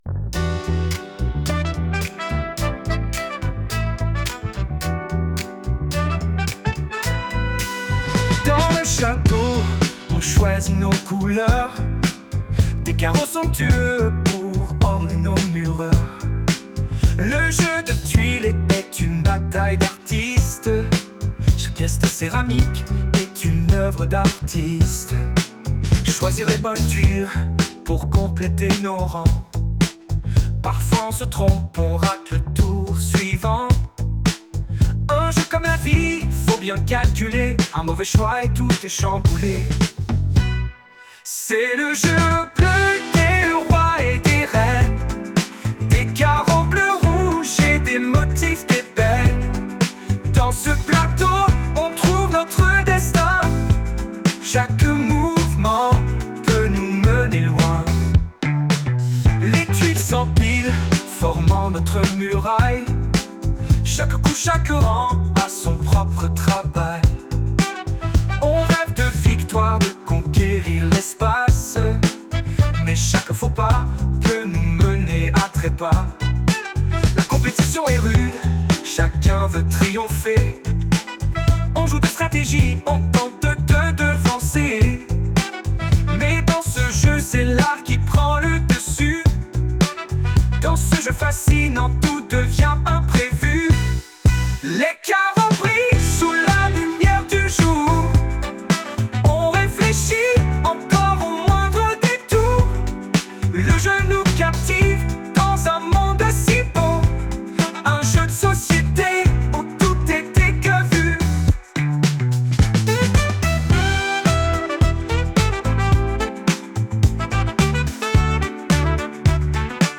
Pour la suivante il y a clairement quelques d�faut de prononciation de la part �du chanteur, mais bon, rien de bien grave pour trouver le jeu suivant : musique jeu 38